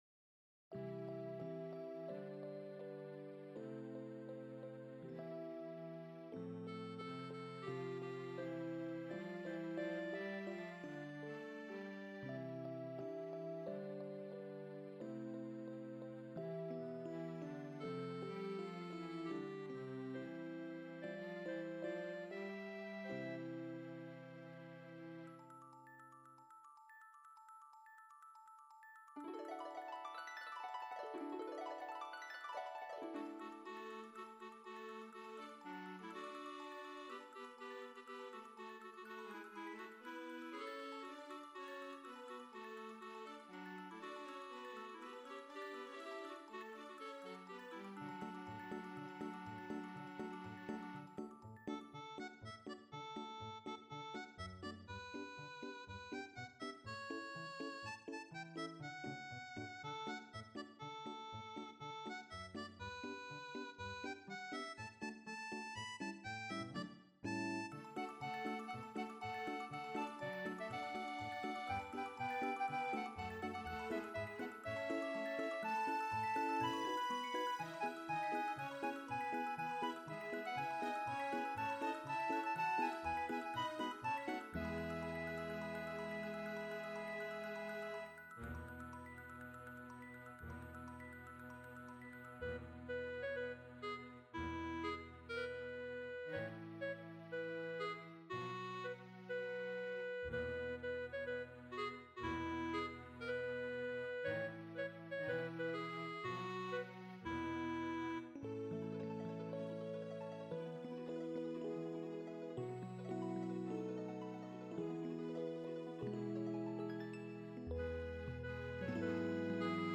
Musique : Medley de Noël
xmasmed.mp3